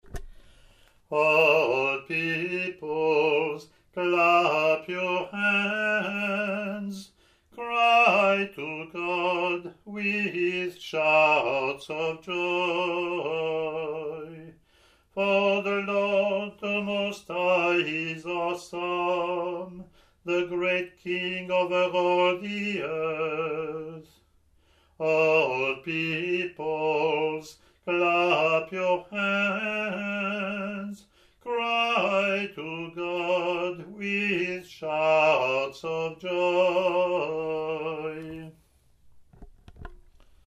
ot13-introit-eng-lm.mp3